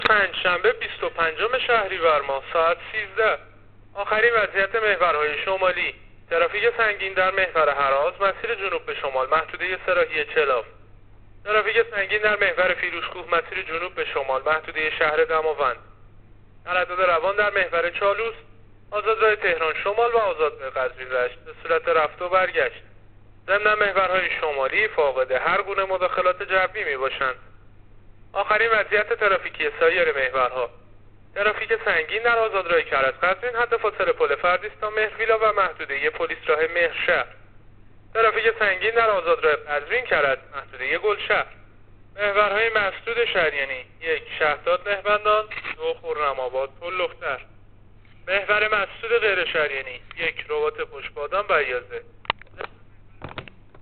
گزارش رادیو اینترنتی از آخرین وضعیت ترافیکی جاده‌ها تا ساعت ۱۳ بیست‌وپنجم شهریور؛